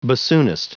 Prononciation du mot bassoonist en anglais (fichier audio)
Prononciation du mot : bassoonist